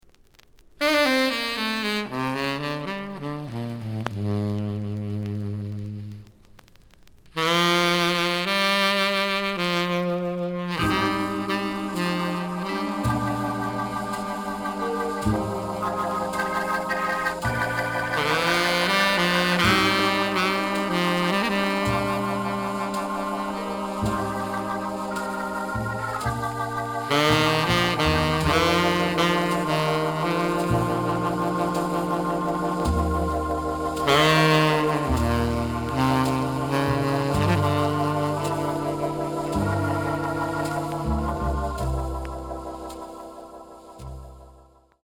The audio sample is recorded from the actual item.
●Genre: Rhythm And Blues / Rock 'n' Roll
Some damage on both side labels. Plays good.)